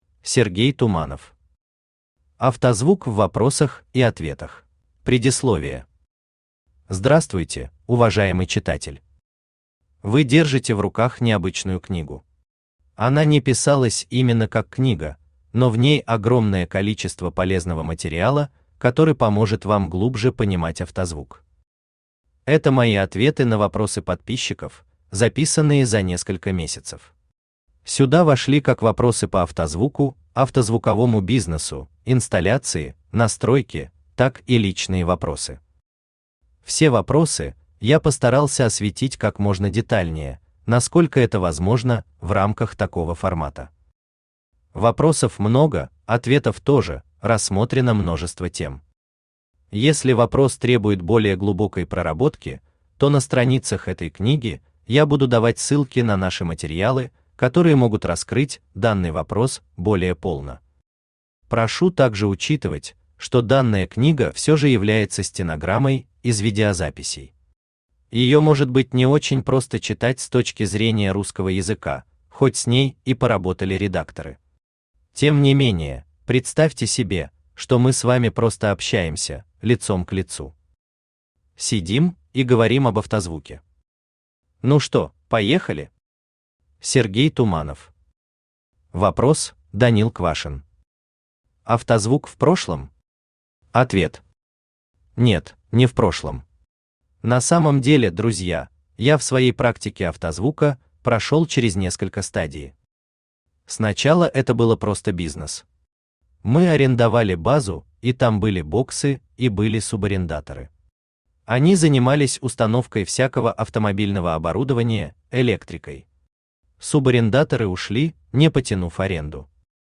Аудиокнига Автозвук в вопросах и ответах | Библиотека аудиокниг
Aудиокнига Автозвук в вопросах и ответах Автор Сергей Александрович Туманов Читает аудиокнигу Авточтец ЛитРес.